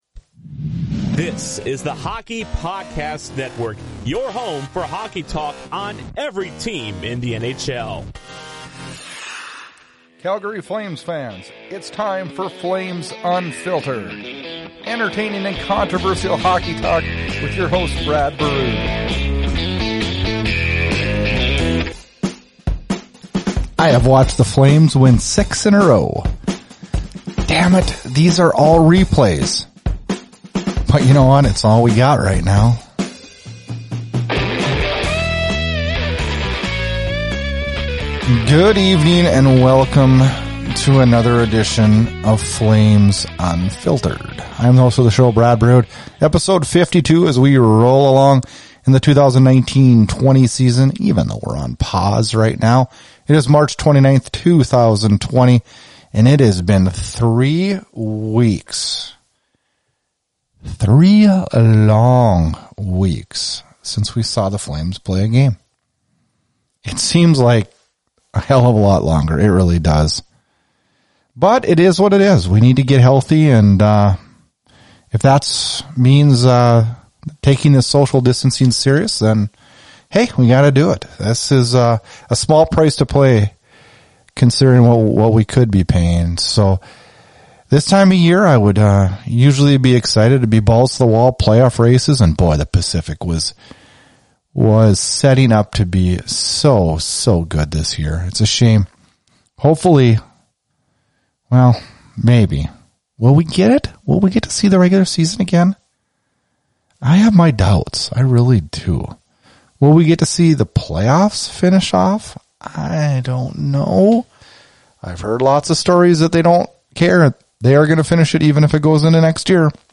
Coronavirus Update as it relates to the NHL Interview